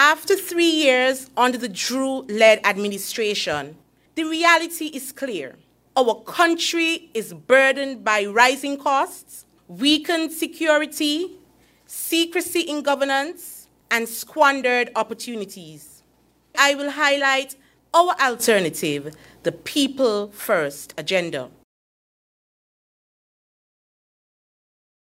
That was Leader of the People’s Action Movement (PAM), Mrs. Natasha Grey-Brookes.
During PAM’s press conference on Aug. 12th, the Party Leader provided details about “PAM’’s People First Agenda”.